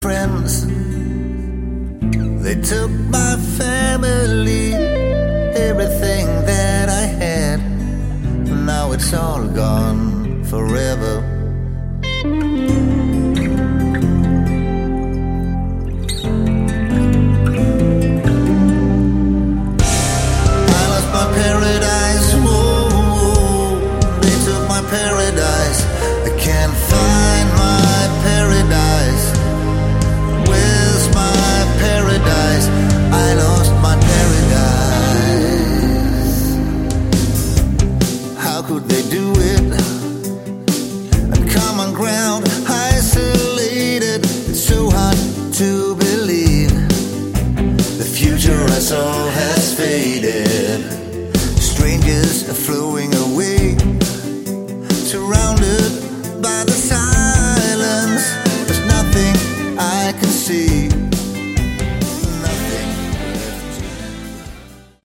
Category: Lite AOR / Westcoast
Lead vocals
Guitars
Percussion
Choir